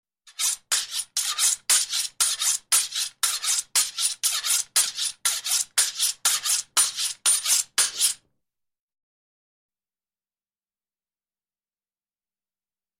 Слушайте онлайн или скачивайте бесплатно резкие, металлические скрежеты и ритмичные движения точильного камня.
Нож об точильный камень